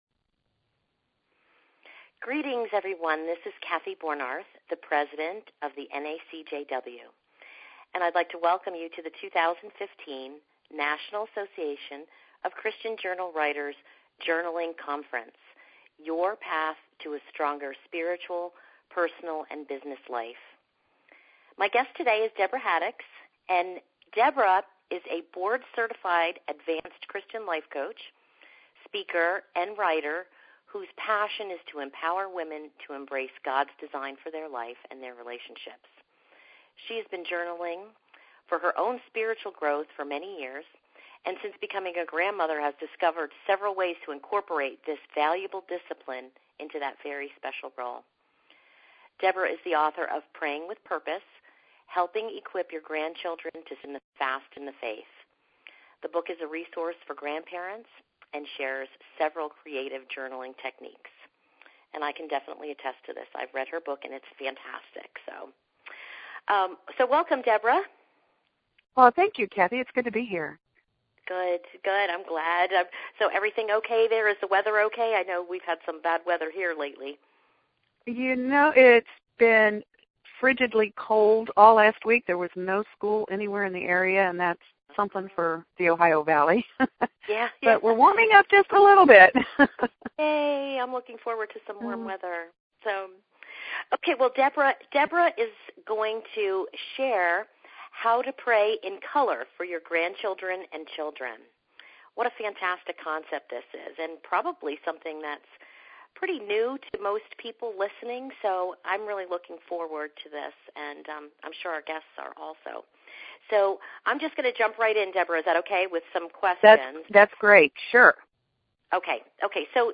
An audio interview on praying in color for your grandchildren and children